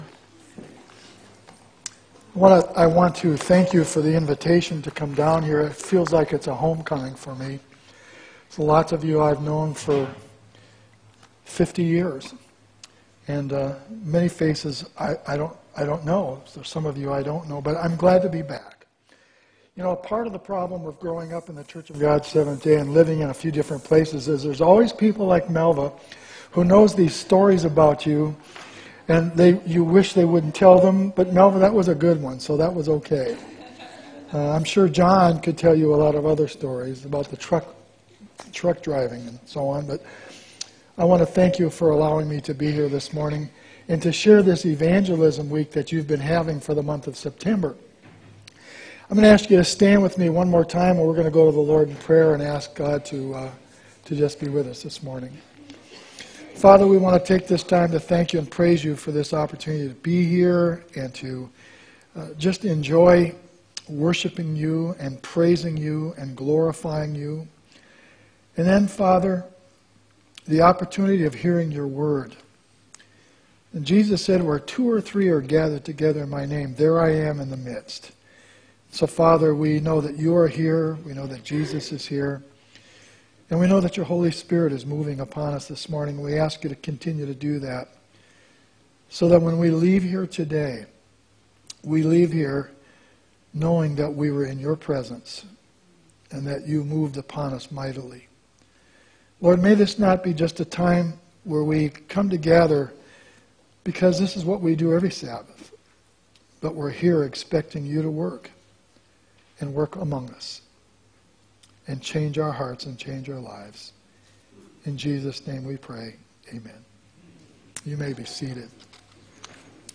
9-23-17 sermon
9-23-17-sermon.m4a